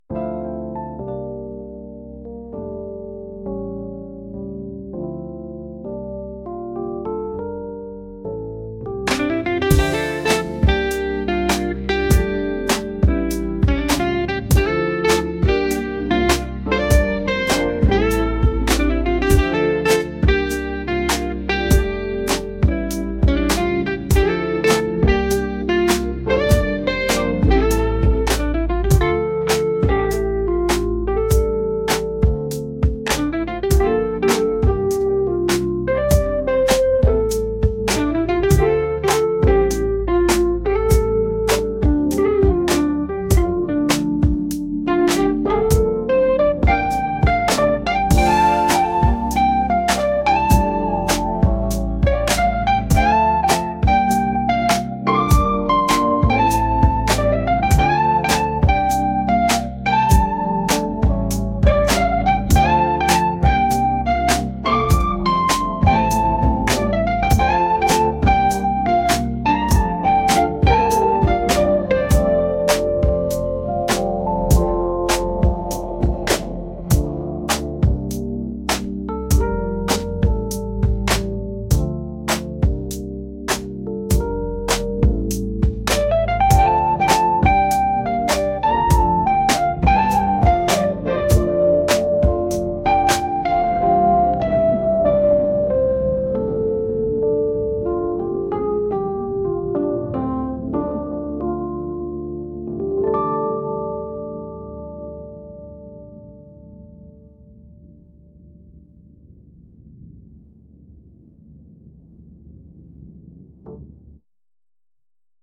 soul & rnb